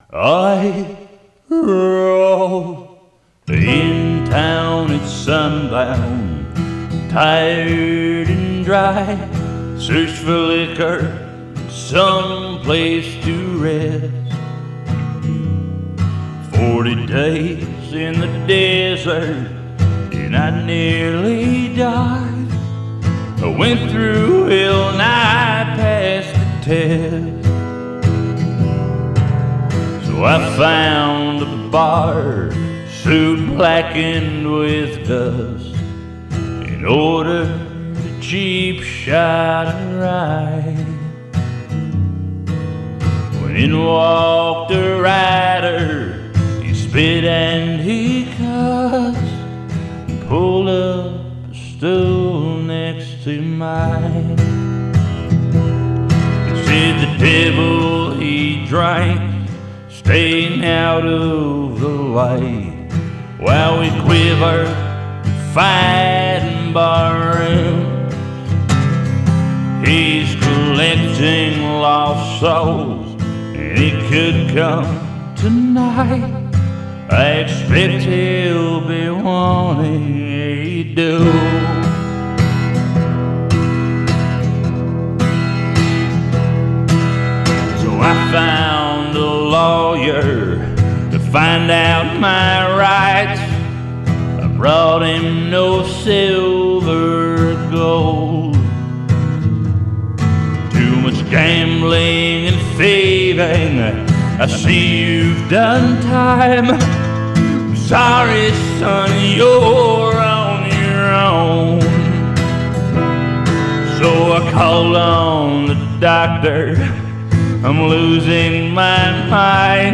sparse and haunting EP